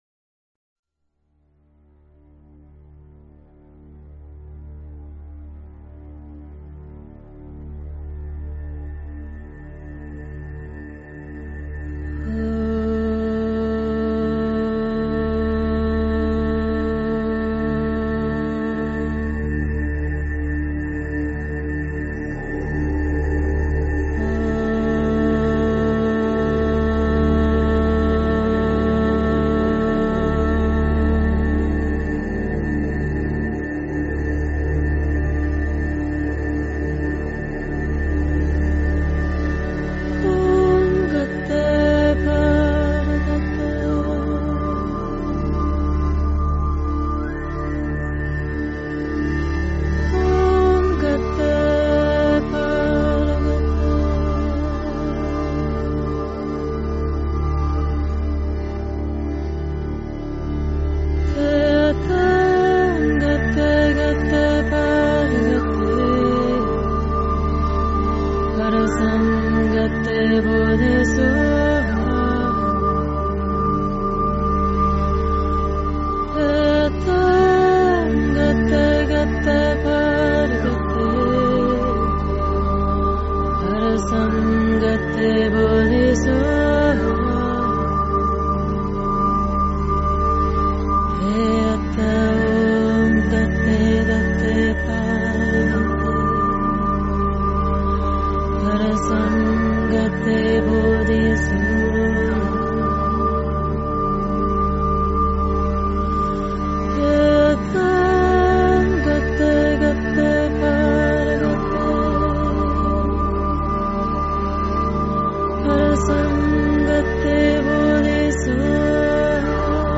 心经 Heart Sutra 诵经 心经 Heart Sutra--未知 点我： 标签: 佛音 诵经 佛教音乐 返回列表 上一篇： 心经 下一篇： 心经(粤语) 相关文章 般若波罗蜜多心经--新韵传音 般若波罗蜜多心经--新韵传音...